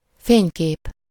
Ääntäminen
IPA : /ˈfəʊ.təʊ/